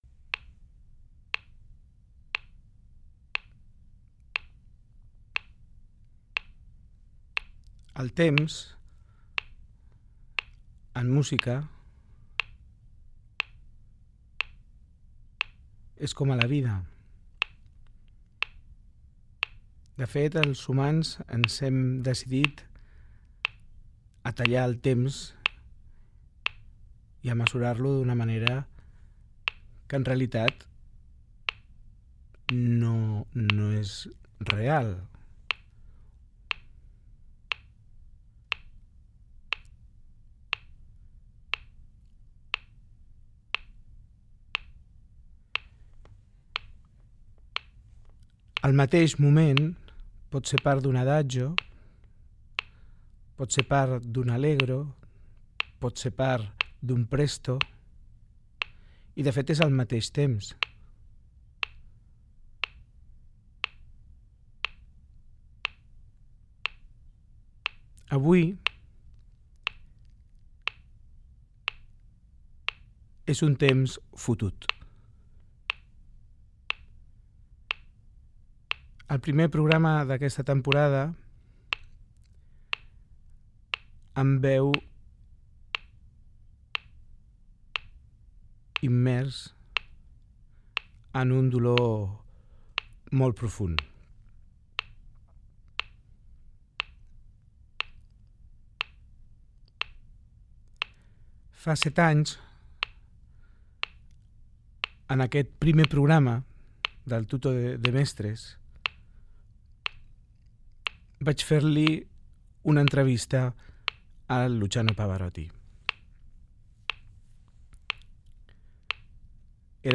Inici del programa en homenatge al tenor Luciano Pavarotti, pocs dies després de la seva mort. So del metrònom i comentari sobre el "tempo", el temps i la mort de Pavarotti
Musical